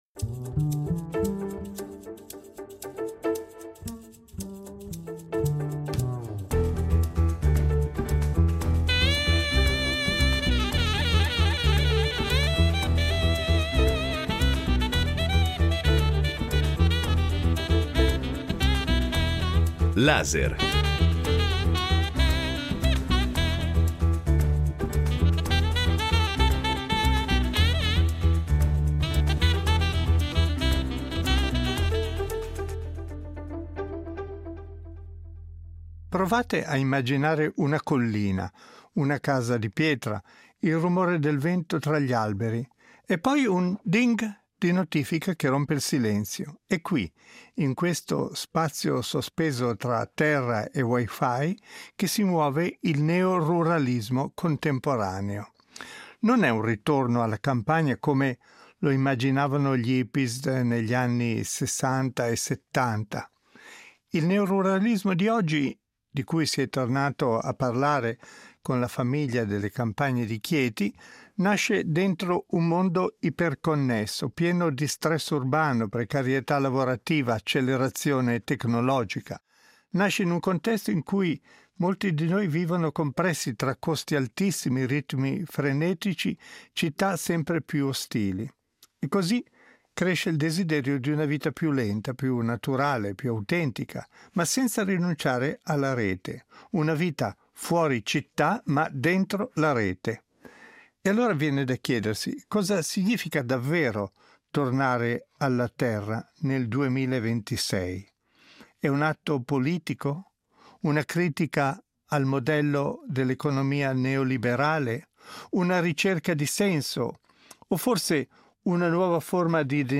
L’obiettivo è capire perché oggi, in un mondo ipertecnologico, cresca il desiderio di tornare alla terra e come questo desiderio si intrecci paradossalmente proprio con la dimensione digitale. Nel corso delle interviste a un sociologo delle culture alternative, a uno studioso di media digitali e a un pedagogista ci chiederemo quali siano le motivazioni profonde ambientali, politiche, psicologiche, economiche, del neoruralismo odierno.